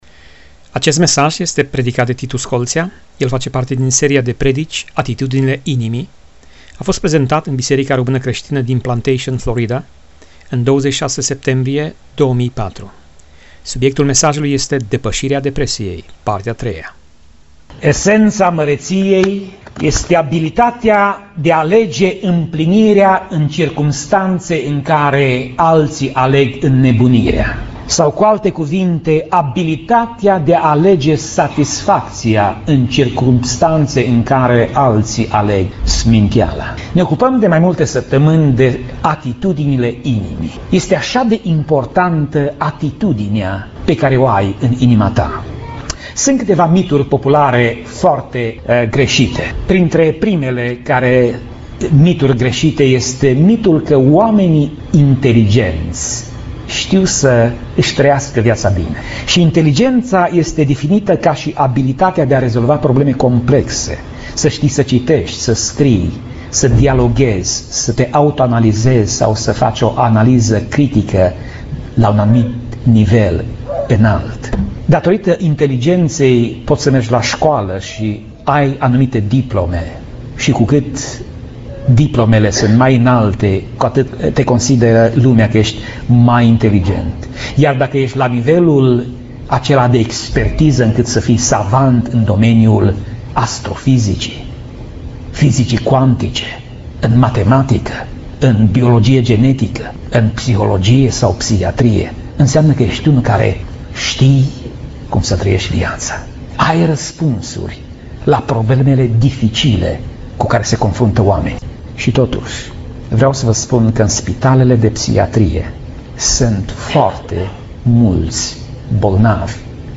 Pasaj Biblie: 1 Imparati 19:1 - 1 Imparati 19:10 Tip Mesaj: Predica